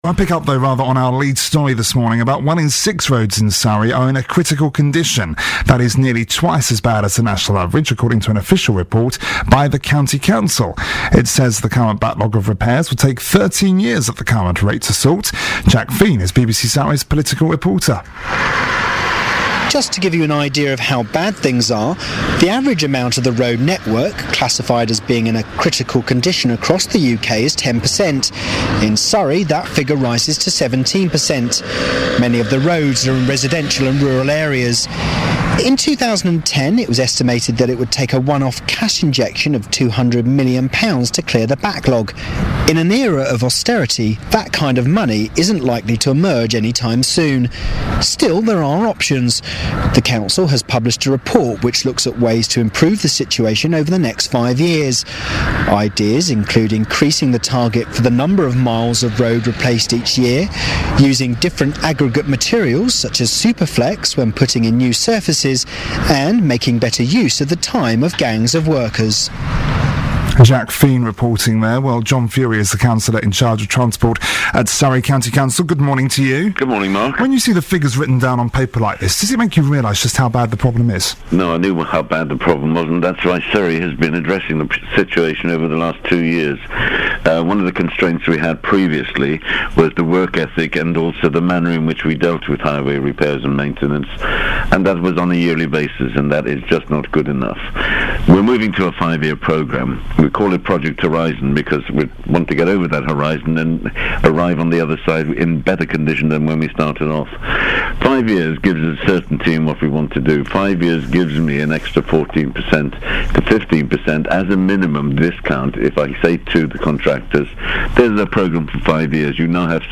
BBC Surrey interview on plans to impove roads
Surrey County Council’s cabinet member for environment and transport John Furey discussed plans to improve Surrey’s roads with BBC Surrey.